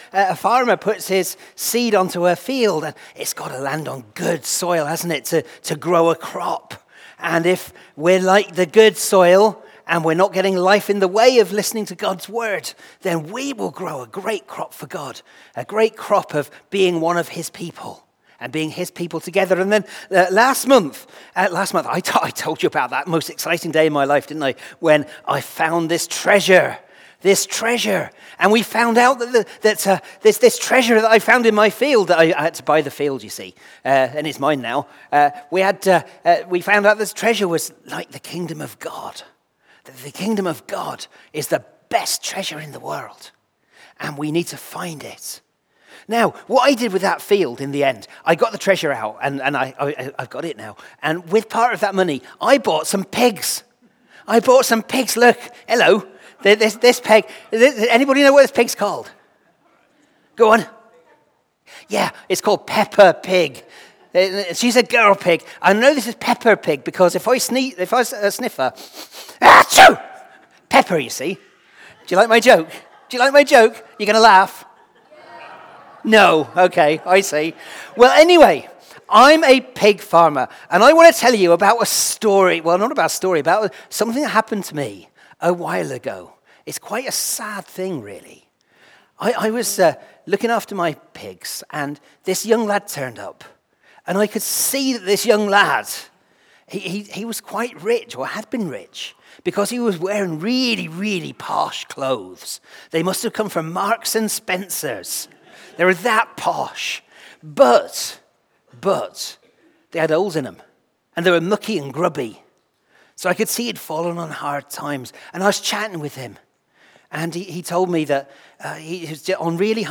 Media for Morning Service on Sun 06th Jul 2025 10:45 Speaker
Passage: Luke 15:11-32 Series: Stories Jesus Told Theme: Sermon Search